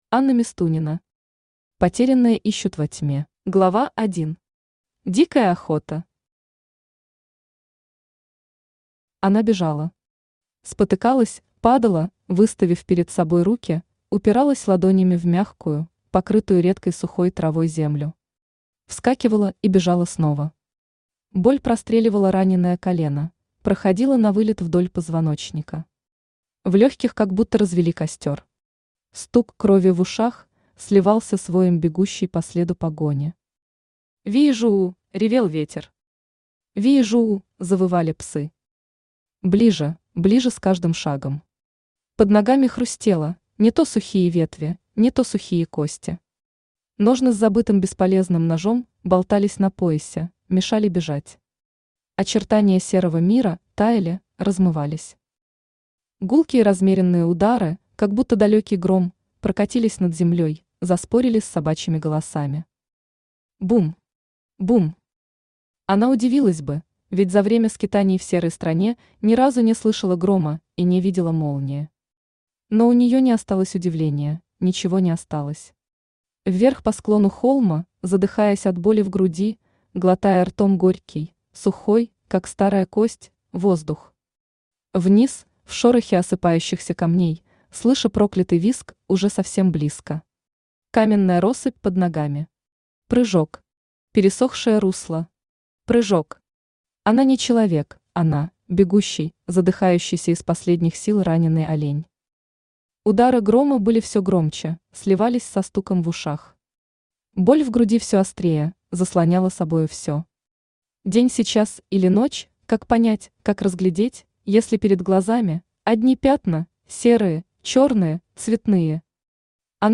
Аудиокнига Потерянное ищут во тьме | Библиотека аудиокниг
Aудиокнига Потерянное ищут во тьме Автор Анна Мистунина Читает аудиокнигу Авточтец ЛитРес.